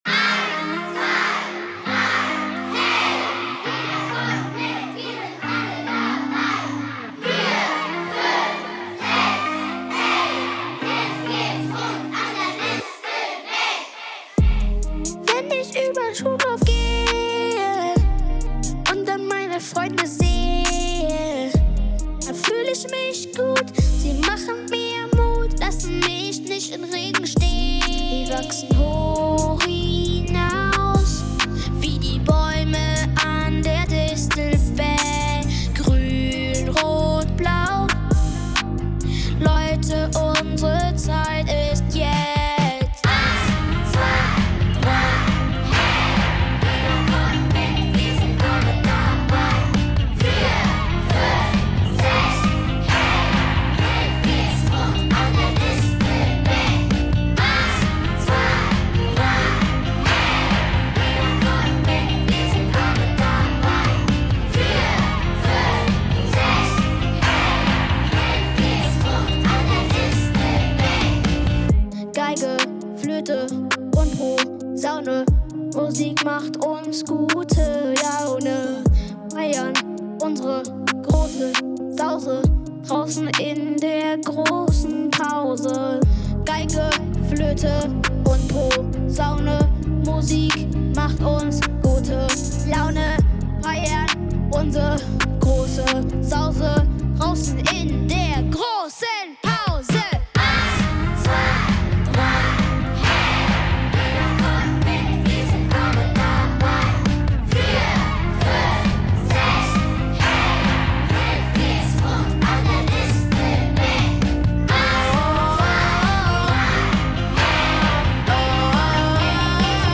© 2023 Grundschule Distelbeck Datenschutz Impressum * Schulsong „Distelbeck“ * 16.12.2022 Liebe Eltern, Wir haben ein großartiges Musikprojekt an unserer Schule durchgeführt.
unsere Schülerinnen und Schüler zusammen ins „Studio“ gebracht
Musikinstrumente, Gesang sowie technische Überarbeitung
Herausgekommen ist ein Top-moderner Song, der alle Register zieht und die Atmosphäre an der Distelbeck super einfängt.